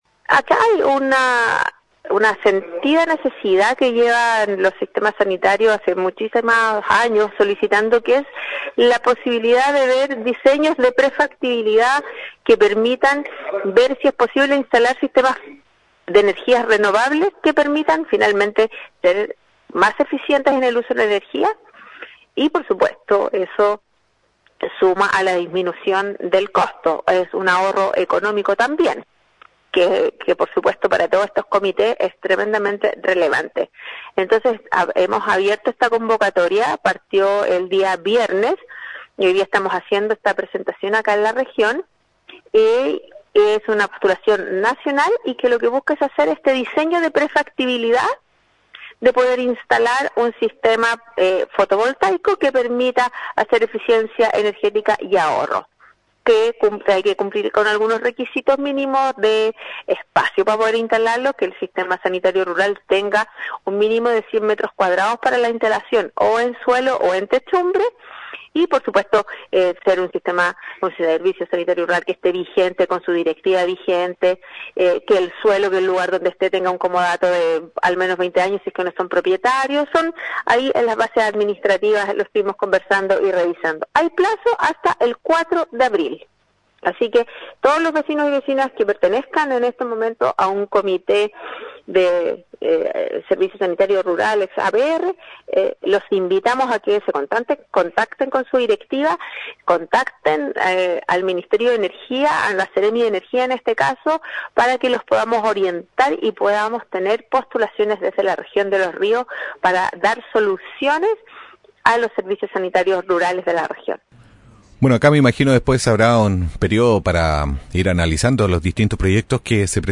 La Seremi de Energía de la región de Los Ríos Claudia Lopetegui, en conversación con Portal Radio señaló los alcances que tiene esta iniciativa, que busca fortalecer el acceso a servicios básicos en zonas rurales y enfrentar los costos energéticos que tienen los proyectos de agua potable rural.